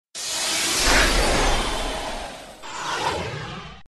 PWR_OFF.mp3